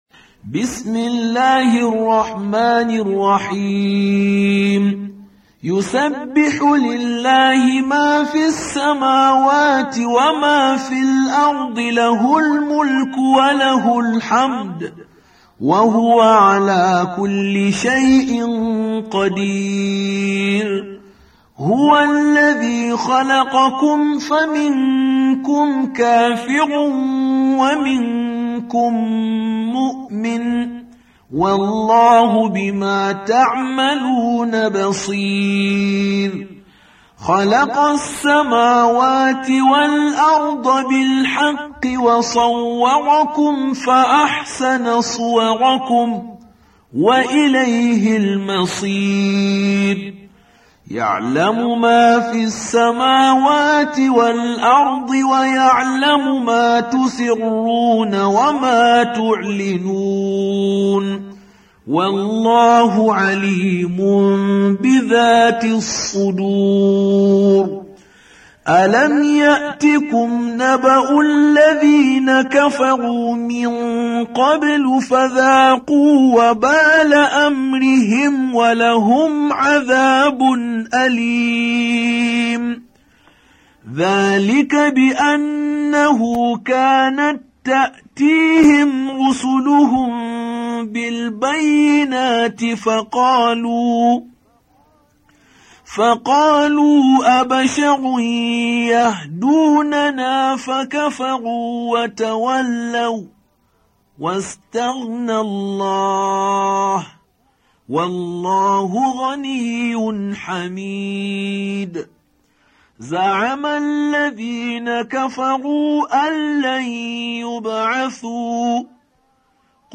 Surah Sequence تتابع السورة Download Surah حمّل السورة Reciting Murattalah Audio for 64. Surah At-Tagh�bun سورة التغابن N.B *Surah Includes Al-Basmalah Reciters Sequents تتابع التلاوات Reciters Repeats تكرار التلاوات